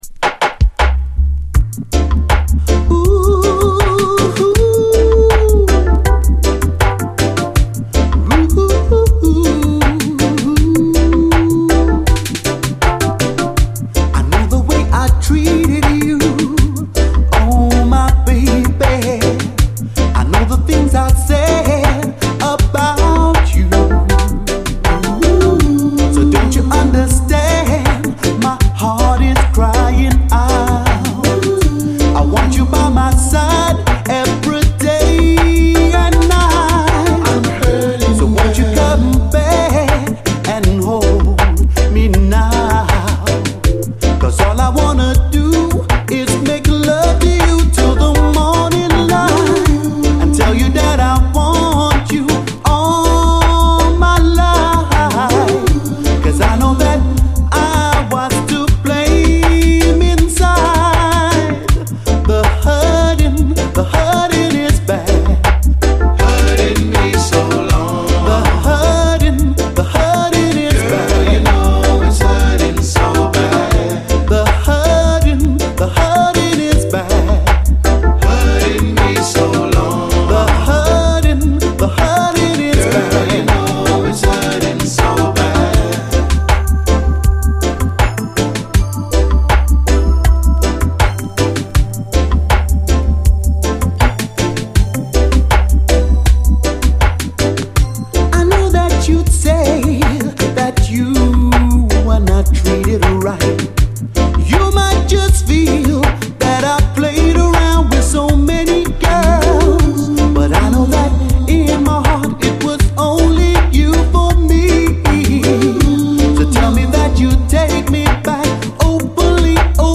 REGGAE
伝統を受け継ぐシルキーなコーラス・ハーモニー！